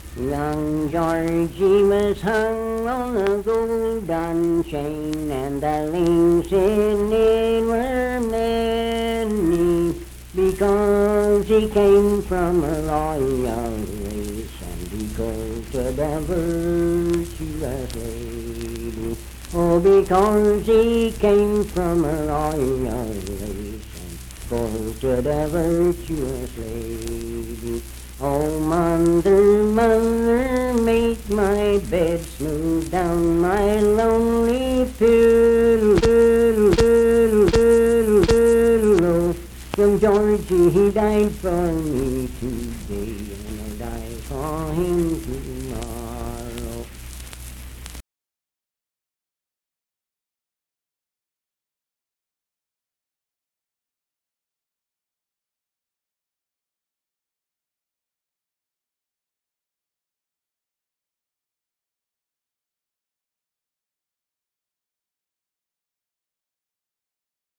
Unaccompanied vocal music
Verse-refrain 2(6w/R).
Performed in Sandyville, Jackson County, WV.
Voice (sung)